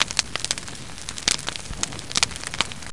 feuer_converted-2.wav